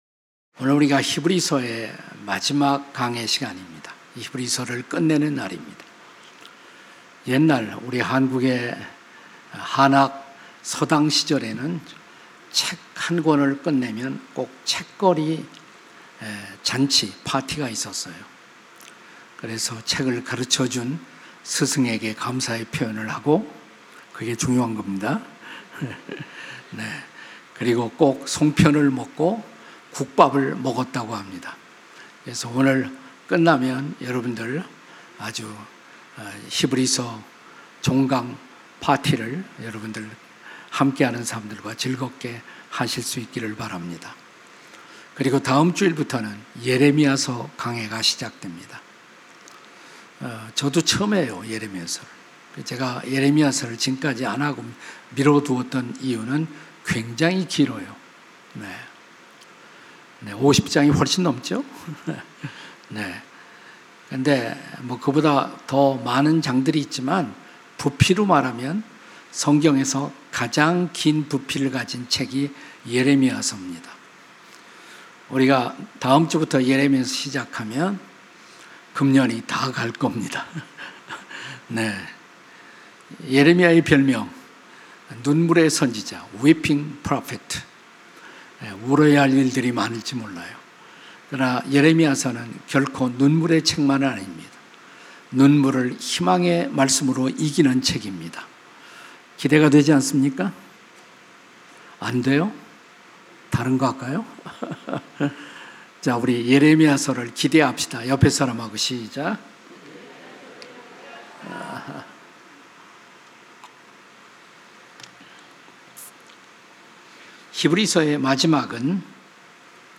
설교 : 주일예배